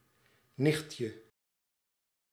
Ääntäminen
Ääntäminen France: IPA: [njɛs] Haettu sana löytyi näillä lähdekielillä: ranska Käännös Ääninäyte Substantiivit 1. nicht {m} Muut/tuntemattomat 2. nichtje {n} Suku: f .